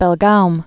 (bĕl-goum)